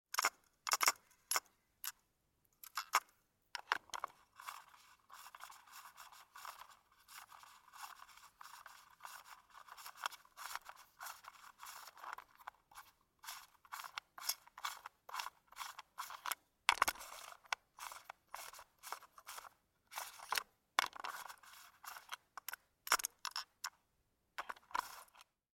Cassette tape